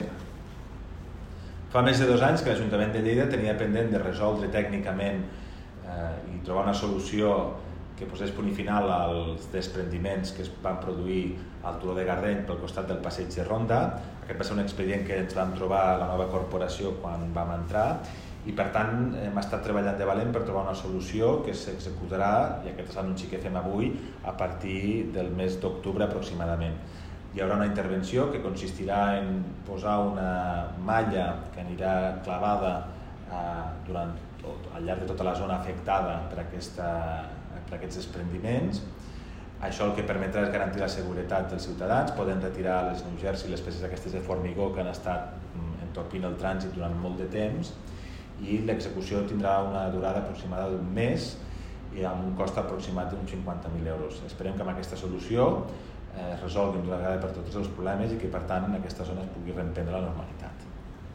tall-de-veu-del-primer-tinent-dalcalde-toni-postius-sobre-les-obres-al-talus-de-gardeny